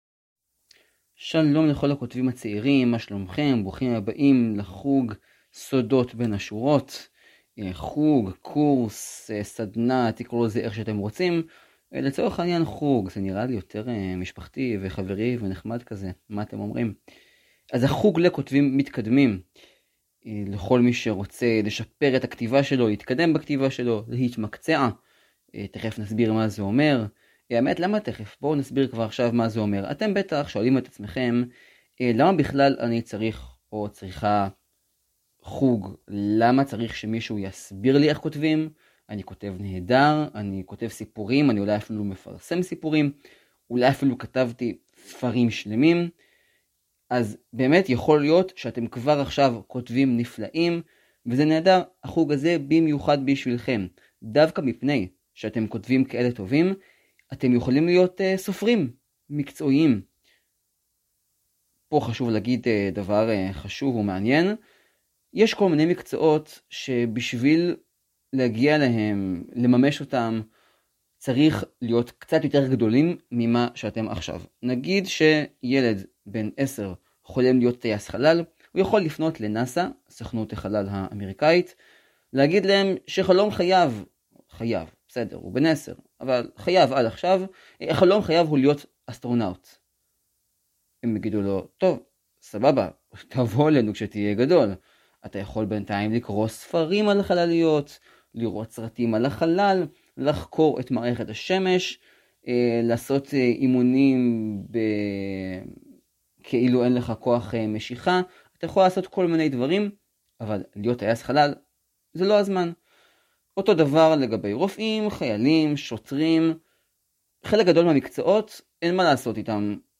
סודות בין השורות – שיעור ראשון חינם!